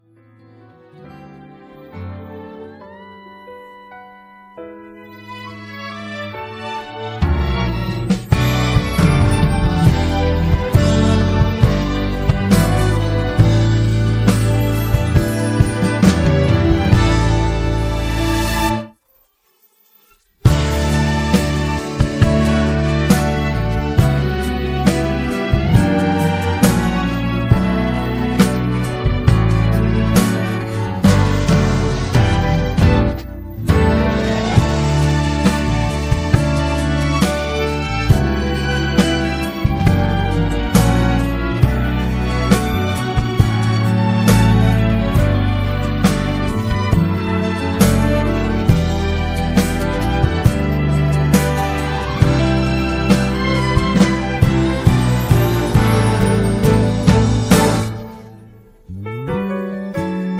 음정 -1키 3:38
장르 가요 구분 Voice Cut